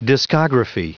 Prononciation du mot discography en anglais (fichier audio)
Prononciation du mot : discography